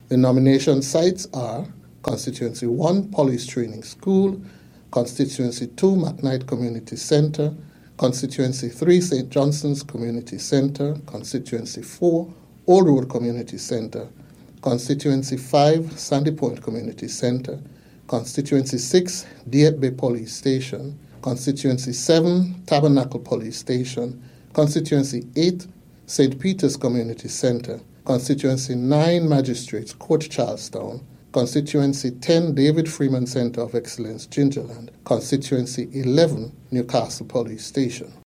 In an address on Wednesday, Mr. Elvin Bailey listed the sites in both St. Kitts & Nevis:
Supervisor of Elections, Mr. Elvin Bailey